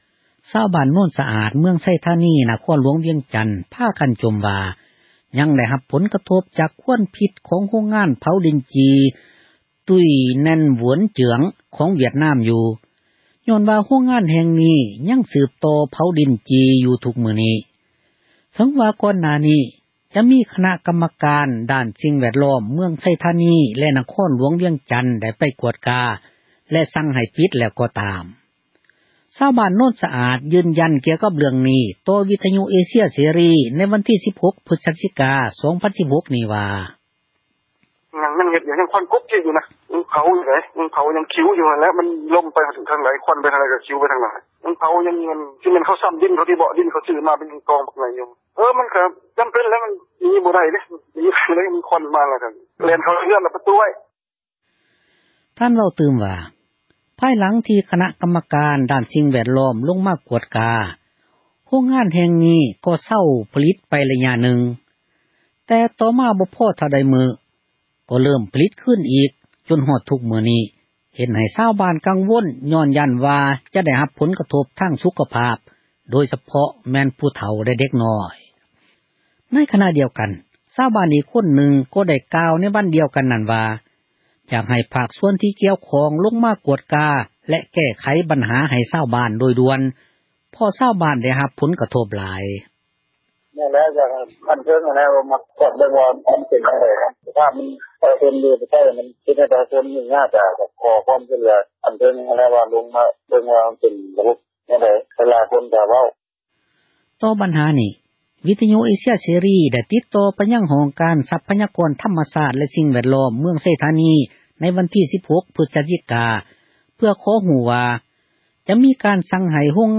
ຊາວບ້ານ ໂນນສະອາດ ຢືນຢັນ ກ່ຽວກັບ ເຣຶ່ອງນີ້ ຕໍ່ ວິທຍຸ ເອເຊັຽ ເສຣີ ໃນວັນທີ 16 ພຶສຈິກາ 2016 ນີ້ວ່າ: